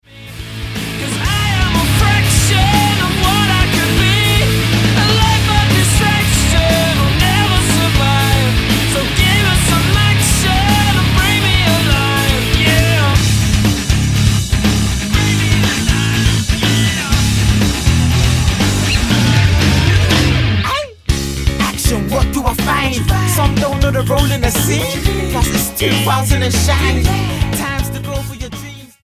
Hard rock and hip-hop fusion
Style: Rock